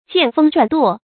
見風轉舵 注音： ㄐㄧㄢˋ ㄈㄥ ㄓㄨㄢˇ ㄉㄨㄛˋ 讀音讀法： 意思解釋： 看風向轉發動舵柄。比喻看勢頭或看別人的眼色行事。